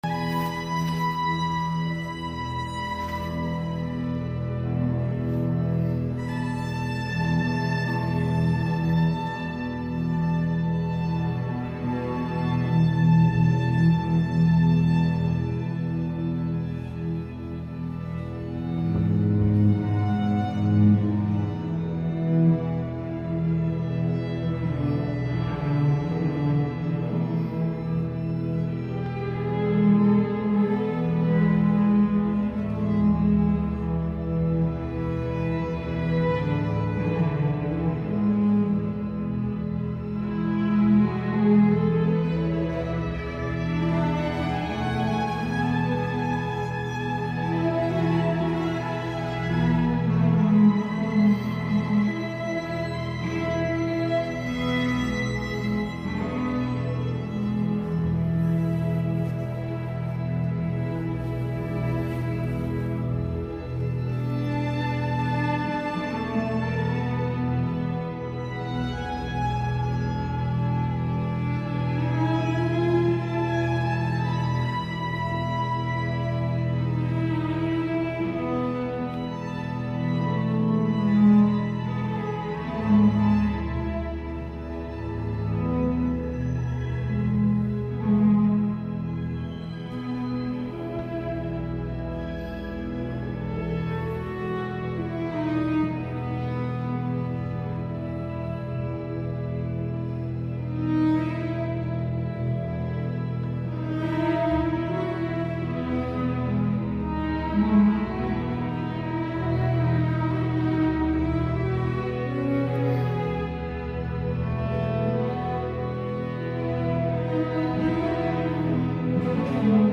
Friedenskonzert 2025 in der Christians Kirche in Ottensen, Altona, Hamburg.
Töne von Marschmusik des Streichorchesters brechen gleich wieder zusammen, wechseln in Differenz, in Demokratie und Vielfalt.
Es ist Vielfalt und Demokratie im Raum und in der Zeit – mit Kontroversen und Harmonien, mit Obacht und Respekt.
Vom Konzert in der Christians Kirche 2025 gibt es hier zwei Audio-Mitschnitte als MP3.